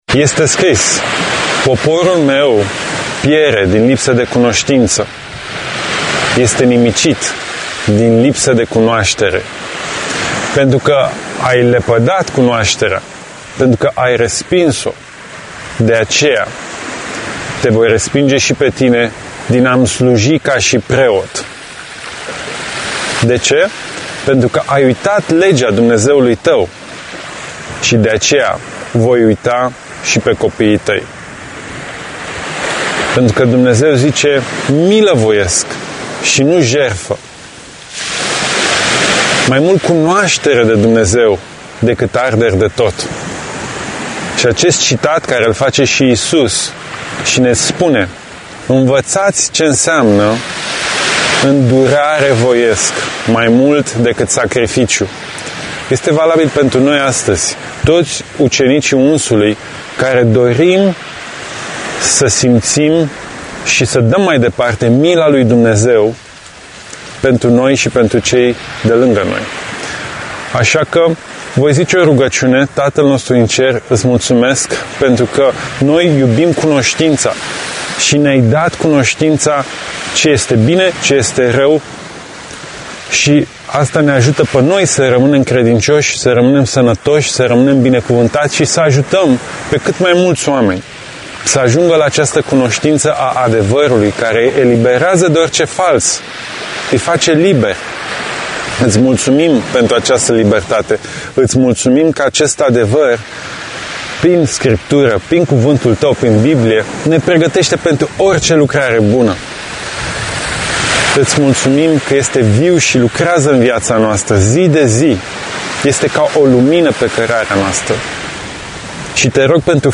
Citirea Bibliei Audio Sapt 44 Osea Explicat El Salveaza